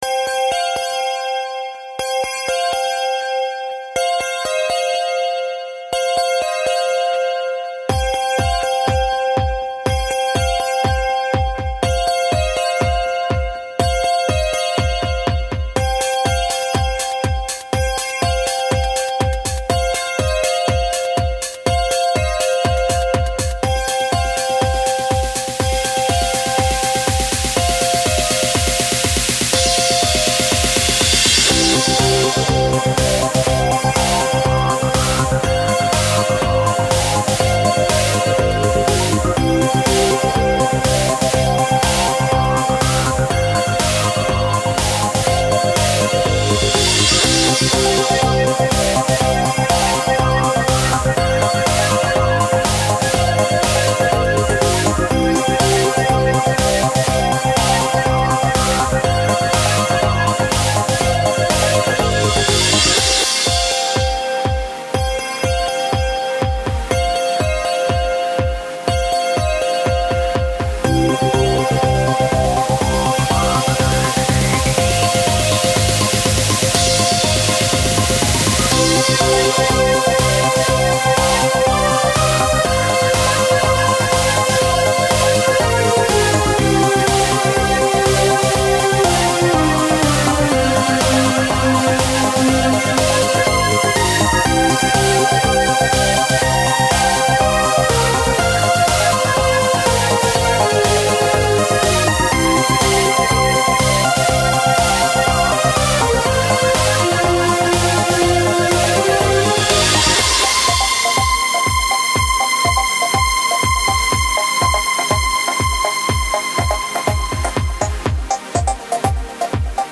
Genre: Trance
授業で製作した4つ打ちループ素材を利用して製作。
ベル系の音で冷たい雰囲気を出した4つ打ちテクノ。